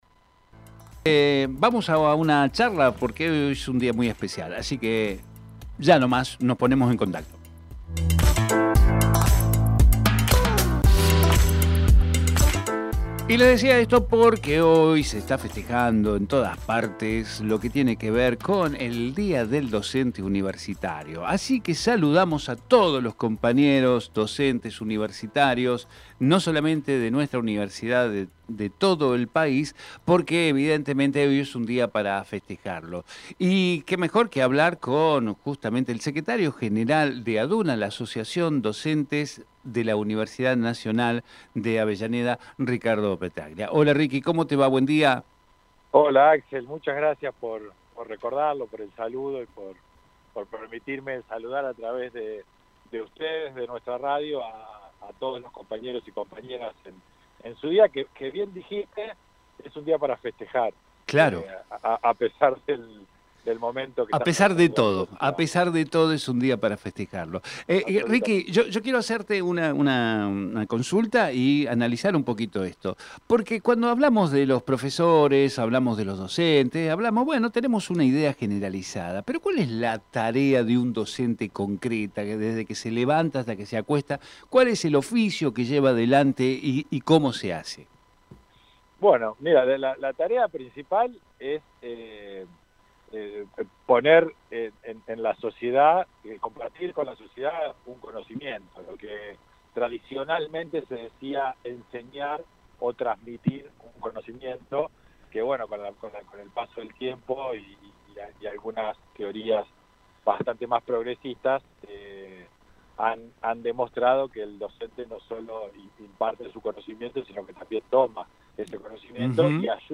Compartimos entrevista realizada en "Territorio Sur"